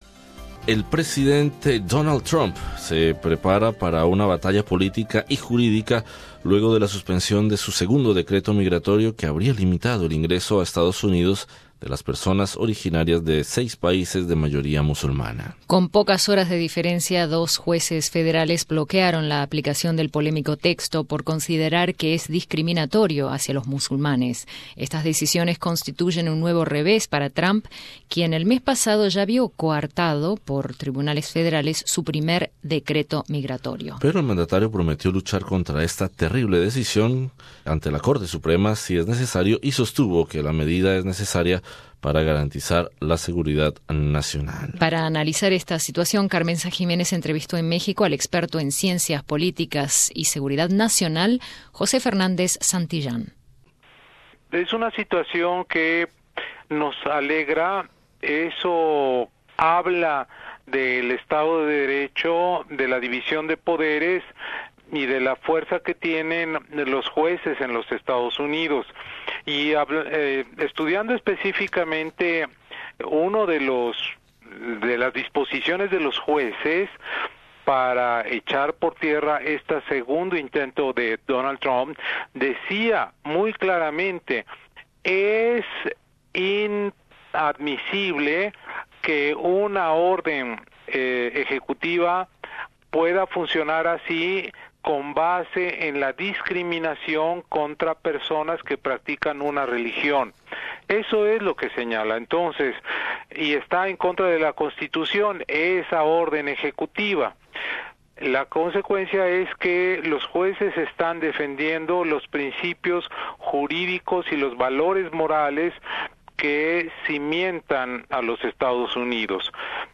Escucha arriba el podcast con la entrevista.